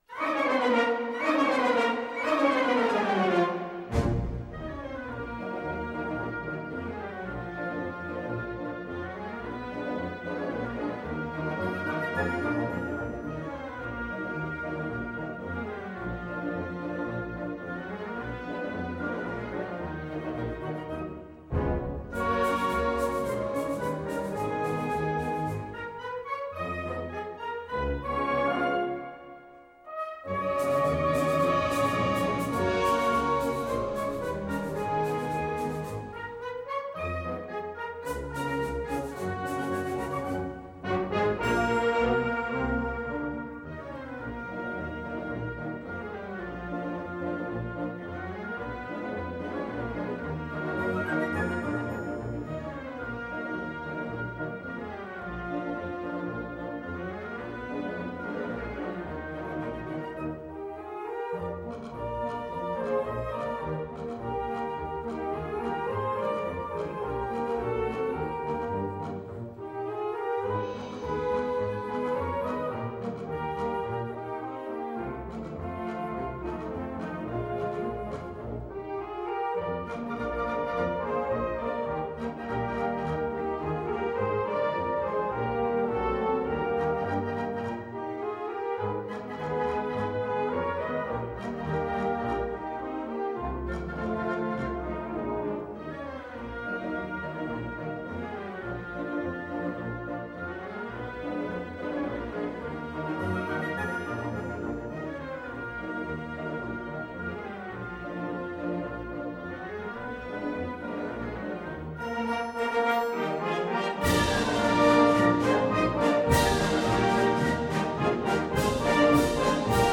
Fox-trot